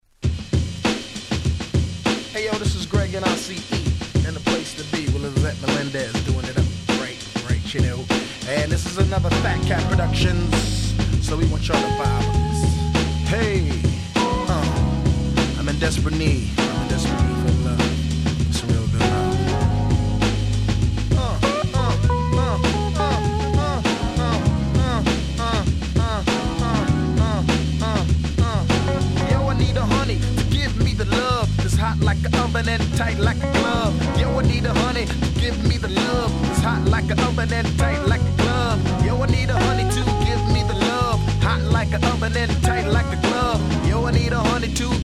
93' 世界的大ヒットR&B !!
『グリグリ、グリグリ、ラ〜ビン』の超キャッチーなサビが嫌でも耳に残る超絶クラシック！